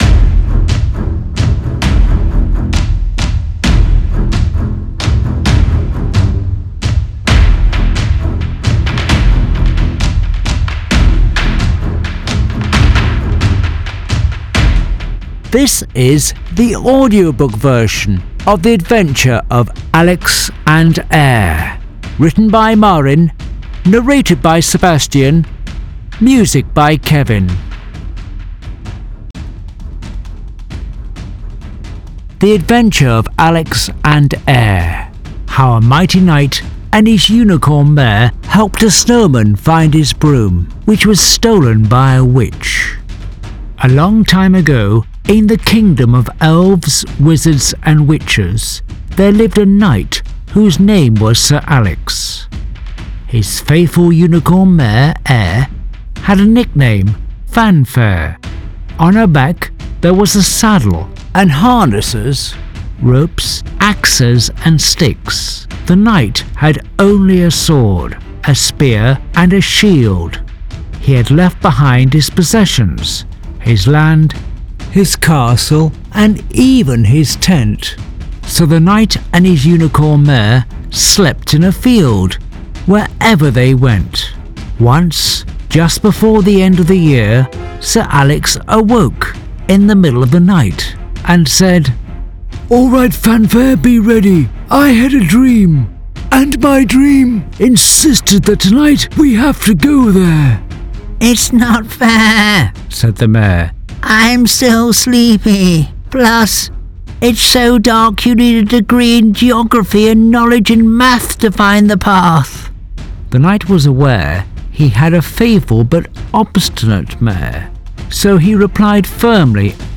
Reading The Adventure of Alex and Er